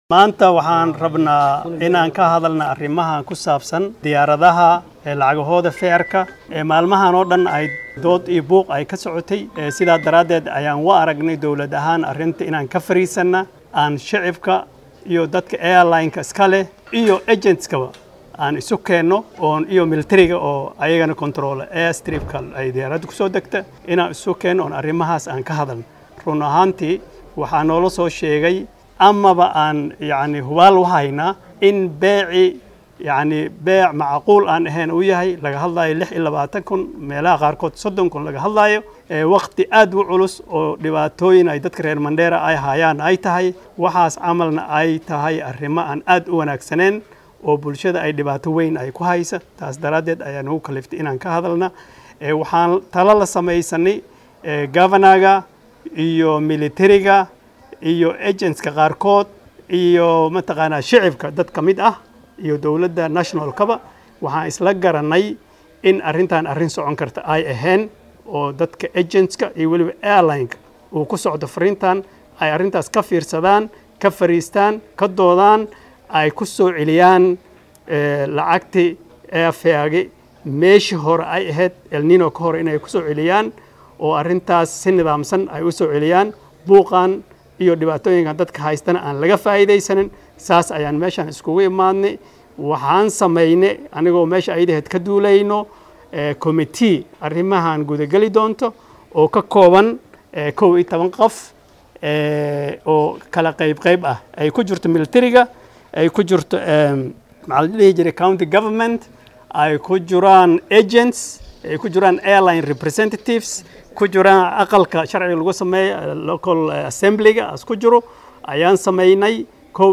Barasaab ku xigeenka ismaamulka Mandera Dr. Cali Macallin oo xalay shir jaraaid ku qabtay magaalada Mandera ayaa waxaa uu ka hadlay qiimaha sare ee lagu raaco diyaaradaha u kala goosho Mandera iyo Nairobi. Waxaa uu sheegay inay guddi ka kooban 11 xubnood u saareen in qiimahan lagu soo celiyo sidii hore.